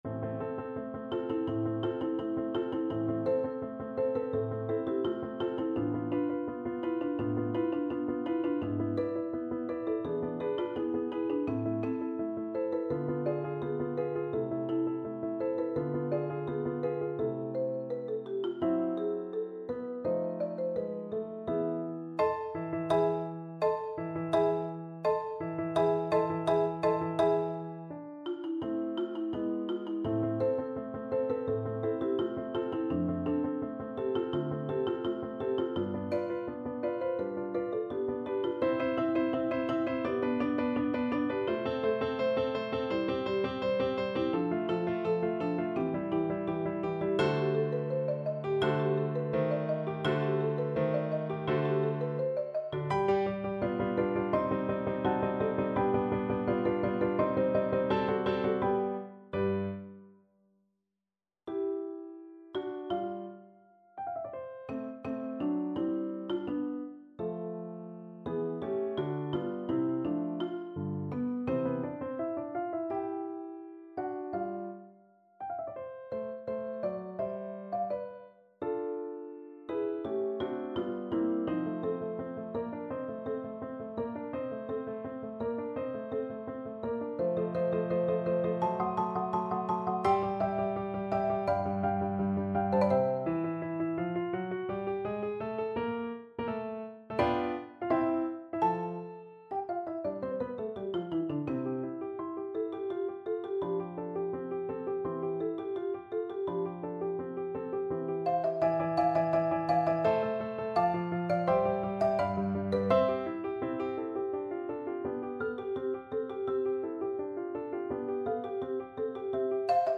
Marimba
Allegro Molto =c.168 (View more music marked Allegro)
4/4 (View more 4/4 Music)
Classical (View more Classical Percussion Music)